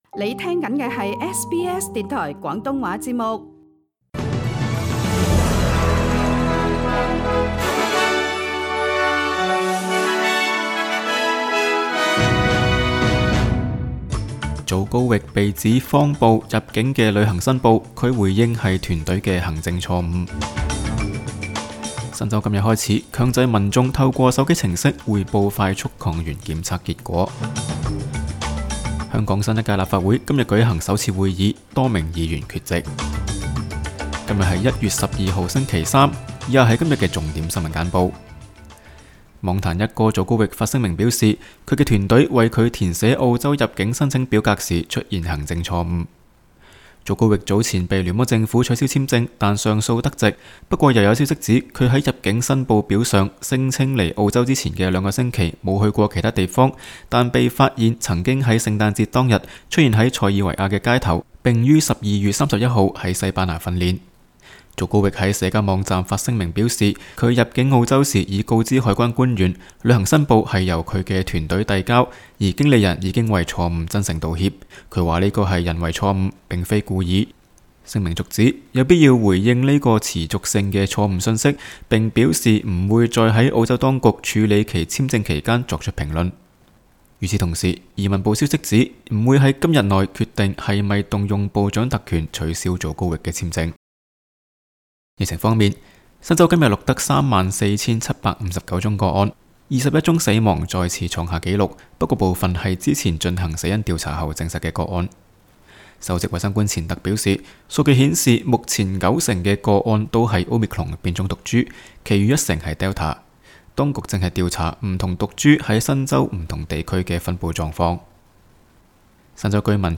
SBS 廣東話節目新聞簡報